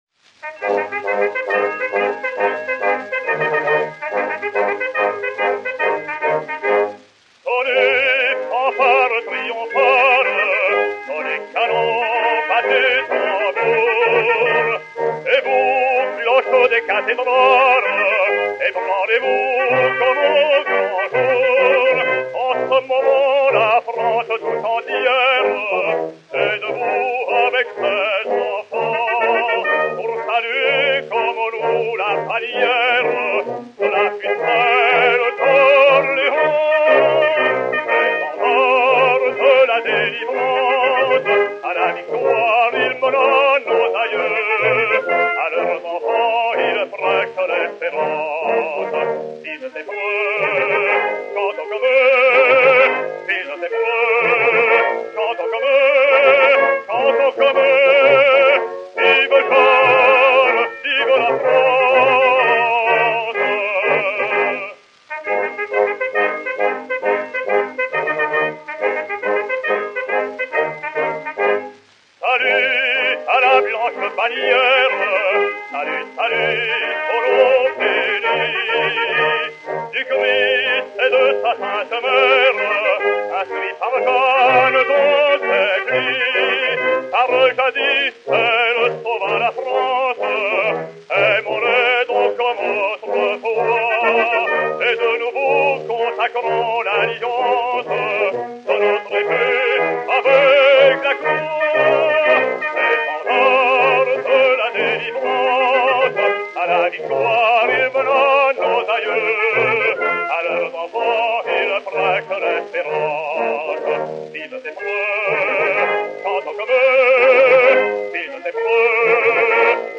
basse française
Orchestre